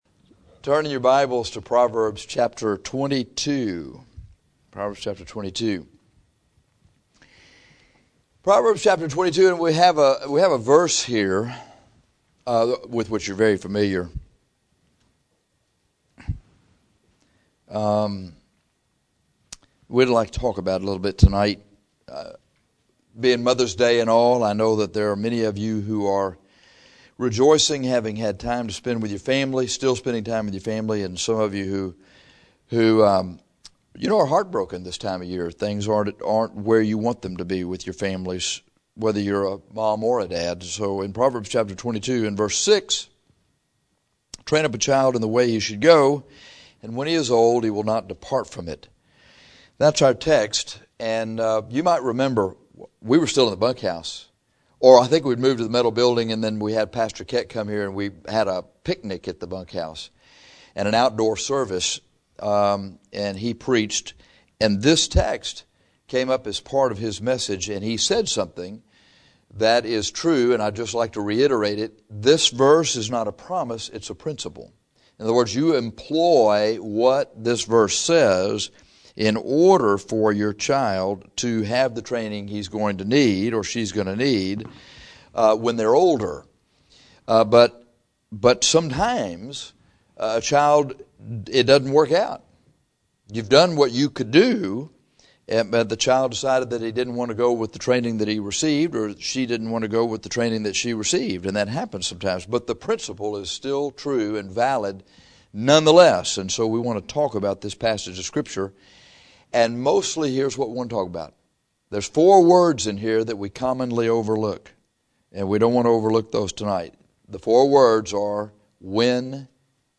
In this sermon, “he” is a reference to your sons and daughters.